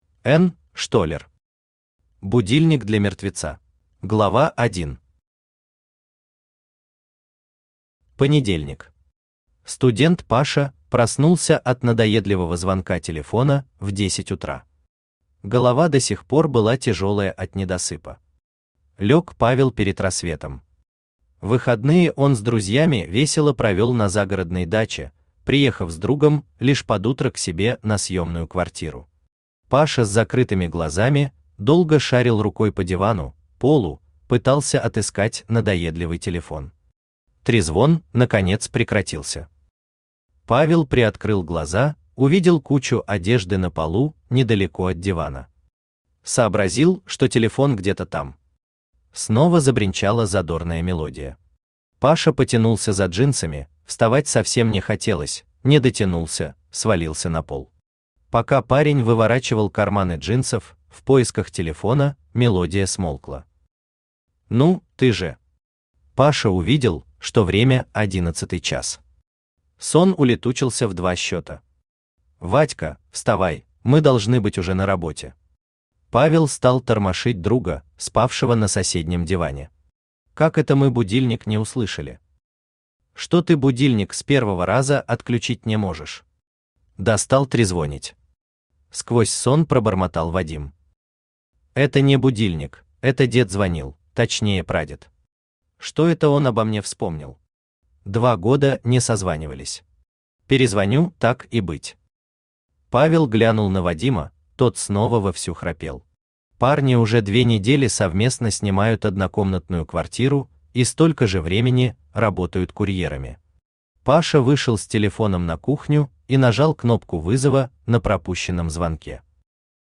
Аудиокнига Будильник для мертвеца | Библиотека аудиокниг
Aудиокнига Будильник для мертвеца Автор Н. Штоллер Читает аудиокнигу Авточтец ЛитРес.